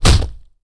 wrenchflesh05.wav